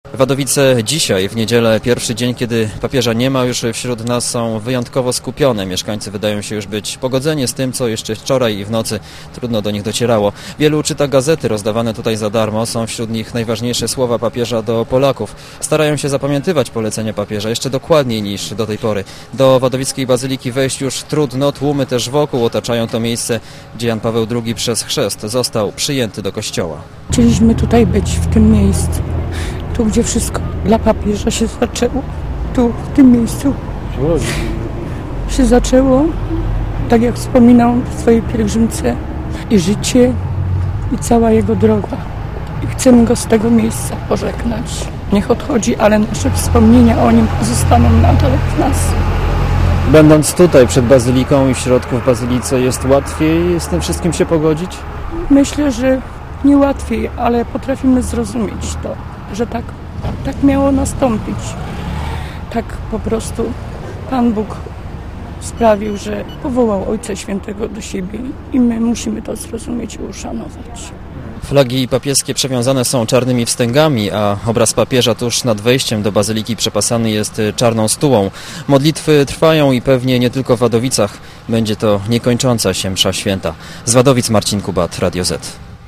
Podobnie jest w Wadowicach.
Relacja
wadowicepo.mp3